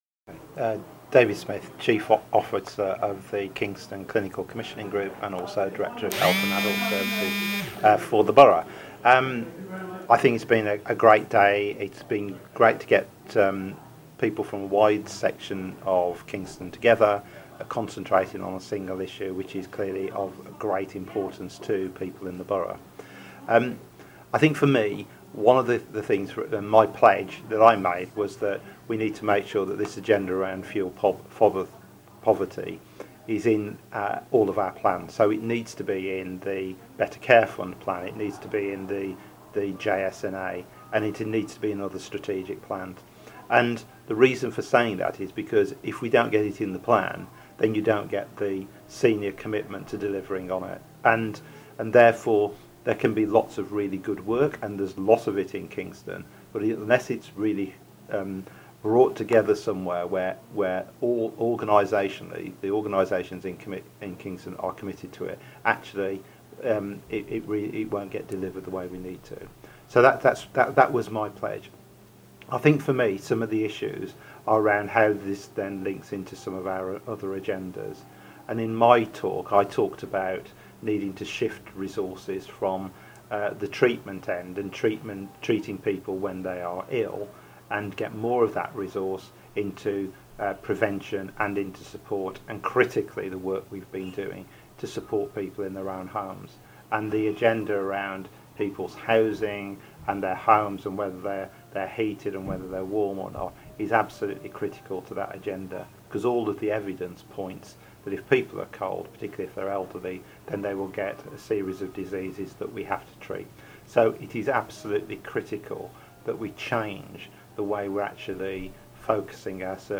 It's been a good day at the KVA Heat or Eat conference
talks about how it could be achieved at the KVA health conference.